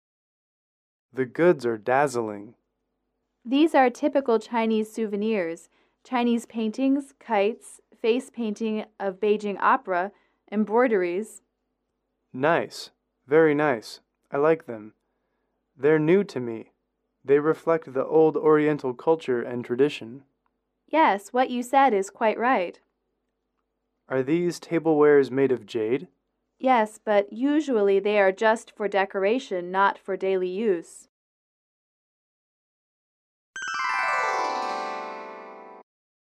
英语口语情景短对话54-3：中国纪念品(MP3)